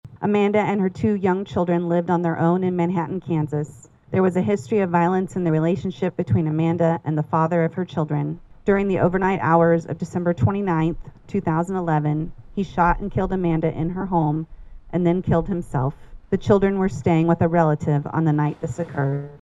A Silent Witness Ceremony was held at the Riley County Courthouse Plaza Wednesday over the lunch hour.
The ceremony included participants reading stories of victims both locally and from around Kansas.